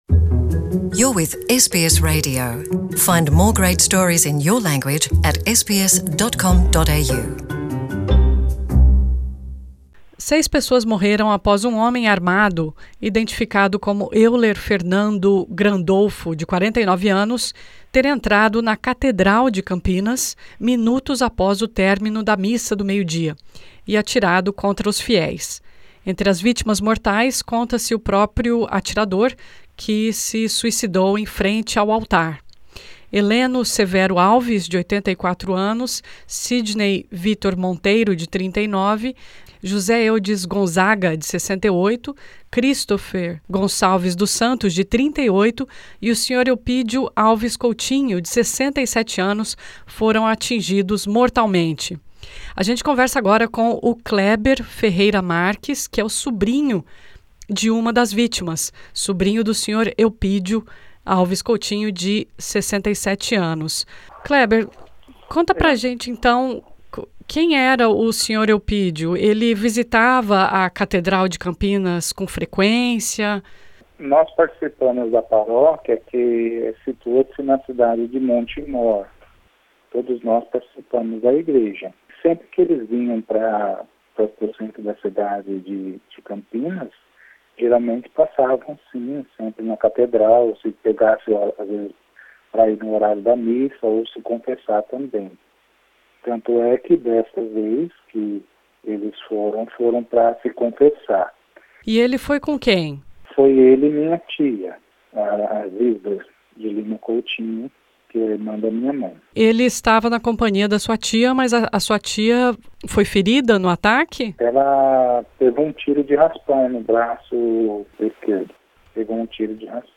Nesta entrevista a SBS em Português